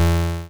Add sound effects for switched emitters.
emitter-off.wav